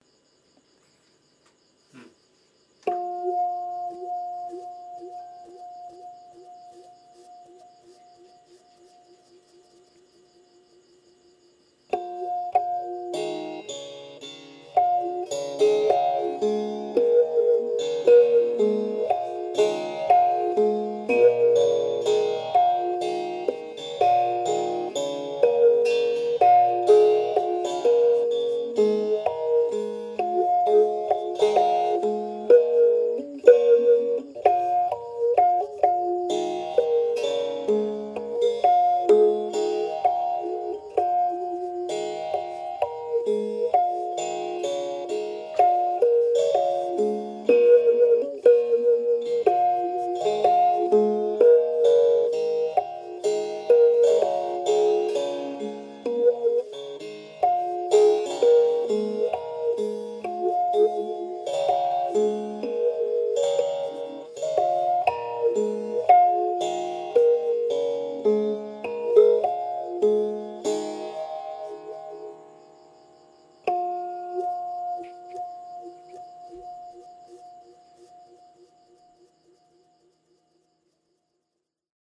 พิณเปี๊ยะ
คำสำคัญ : โฮงซึงหลวง, ปราสาทไหว, แพร่, เมืองลอง, พิณ, พื้นบ้าน, พิณเปี๊ยะ, เครื่องดนตรี, ล้านนา